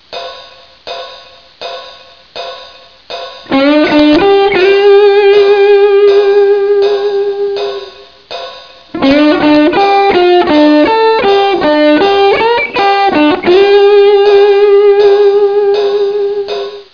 Другой мой любимый пример - Пример 2, который действительно выигрывает от небольшой "встряски".
Мне нравится выделять единственное окончание каждой фразы небольшим "экстра-нечто".